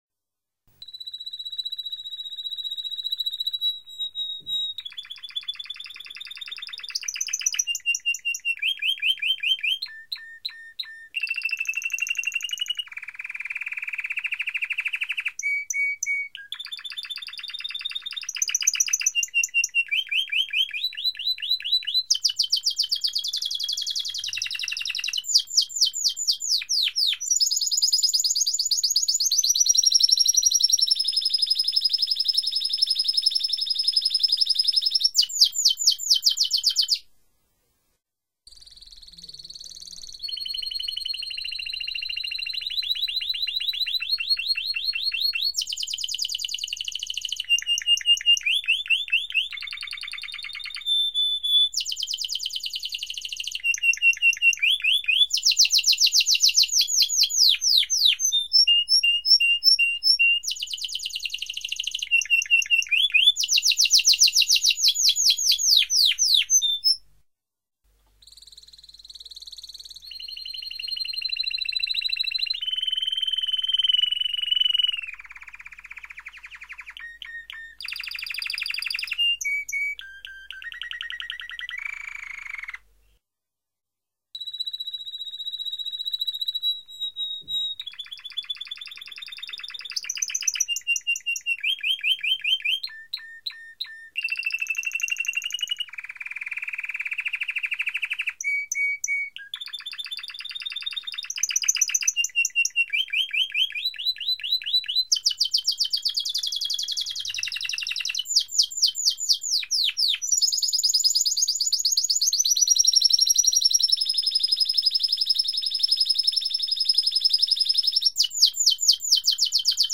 Audio De Timbrado
Audios de Canarios continuos... bajalos y quema tu cd que seguro y te sirve de maestro..